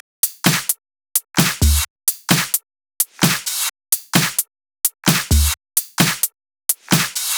VDE 130BPM Change Drums 3.wav